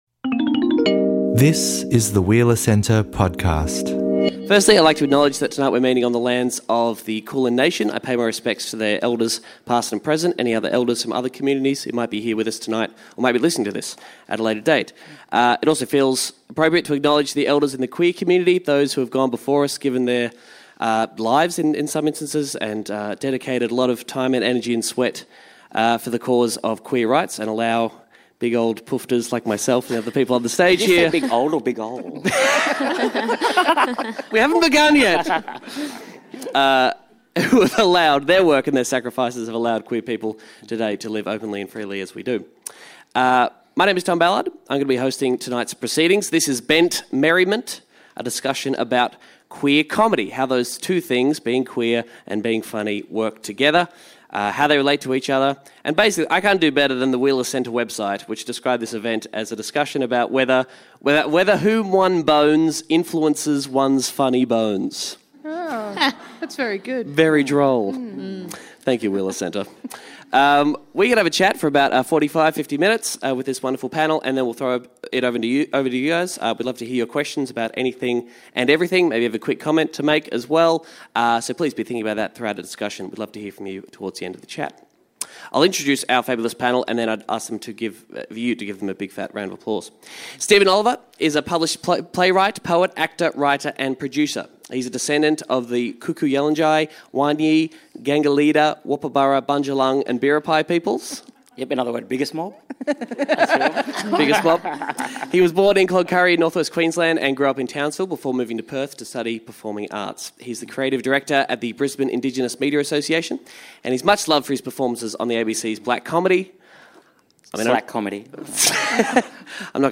Tom Ballard and Steven Oliver In this discussion, hear stories from three queer comedians about what they’ve encountered along the way. How did they begin, and how has their comedy developed alongside their sexuality? In which ways does queer comedy operate as a kind of activism?